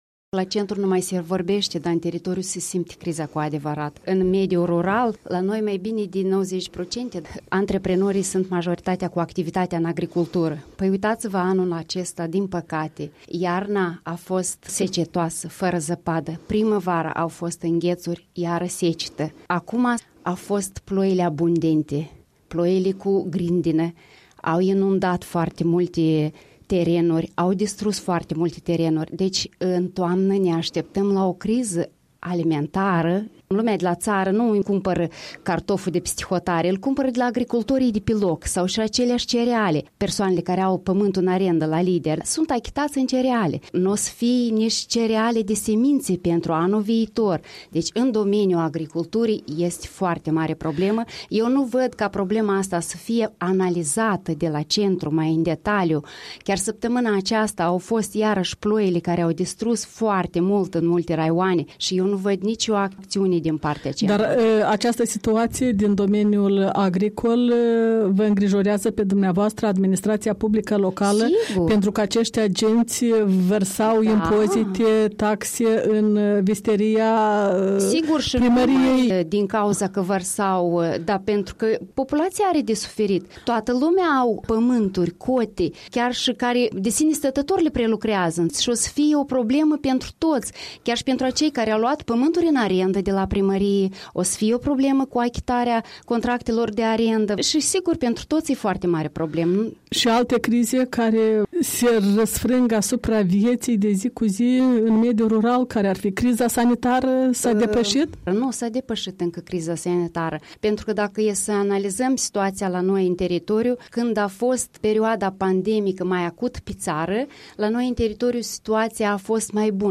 Interviu cu Eugenia Sîrghi, prima la Satul Nou, Cimișlia.